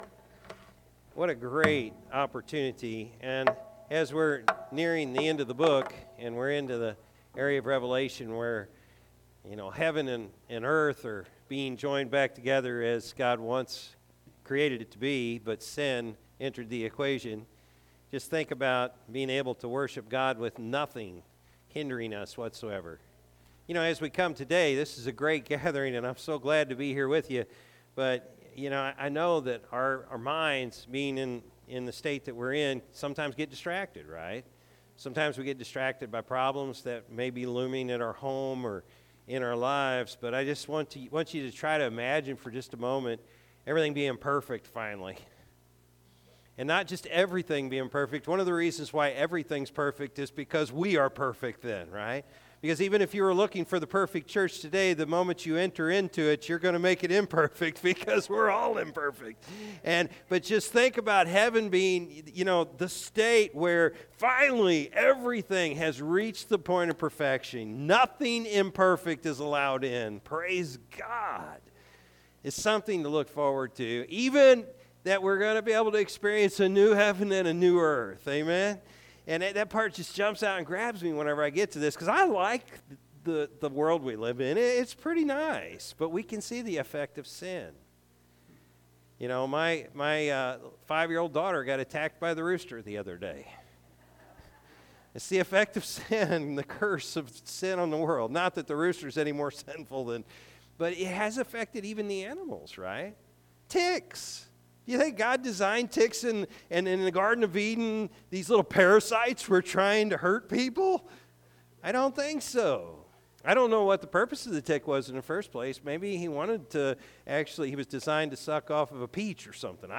June-8-2025-Morning-Service.mp3